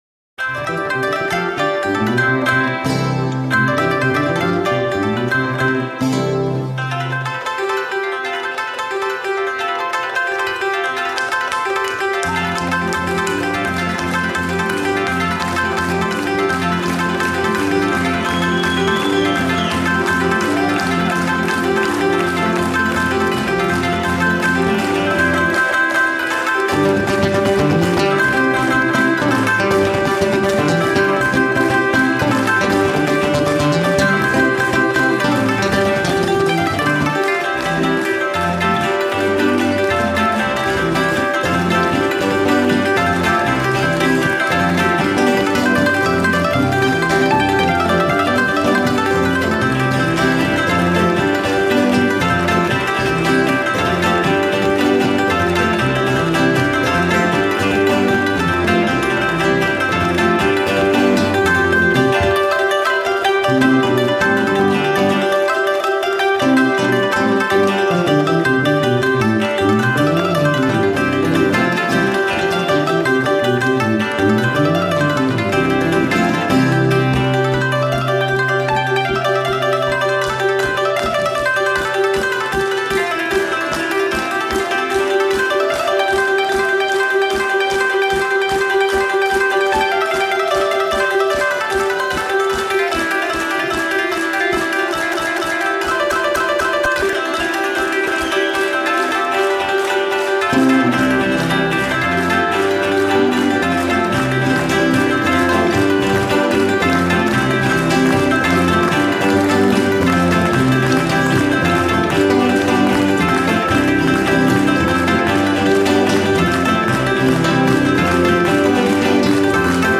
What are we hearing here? Genre: Neofolk